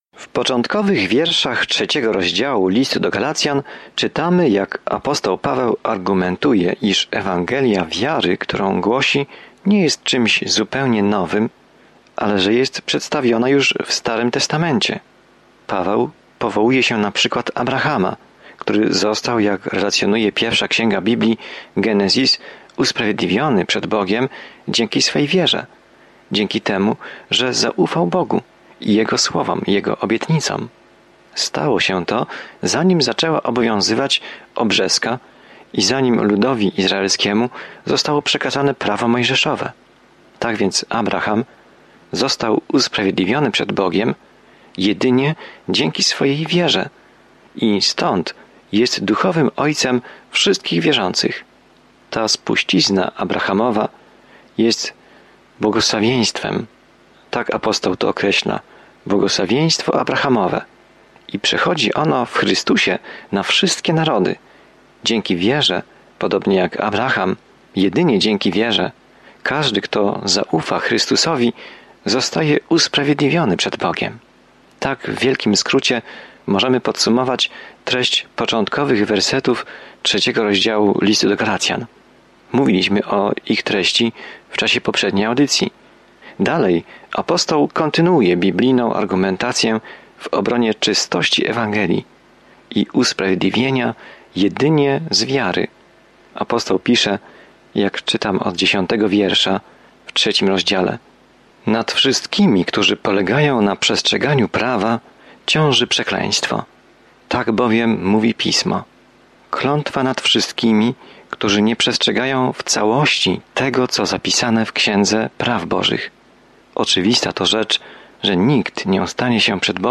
Pismo Święte Galacjan 3:10-14 Dzień 6 Rozpocznij ten plan Dzień 8 O tym planie „Tylko przez wiarę” jesteśmy zbawieni, a nie przez cokolwiek, co czynimy, by zasłużyć na dar zbawienia – takie jest jasne i bezpośrednie przesłanie Listu do Galacjan. Codzienna podróż przez Galacjan, słuchanie studium audio i czytanie wybranych wersetów słowa Bożego.